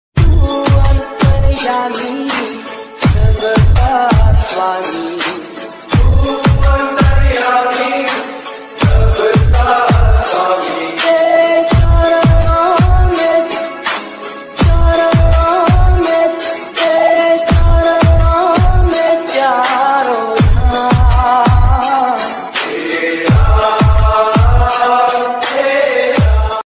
bhakti